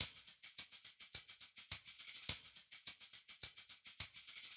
Index of /musicradar/sampled-funk-soul-samples/105bpm/Beats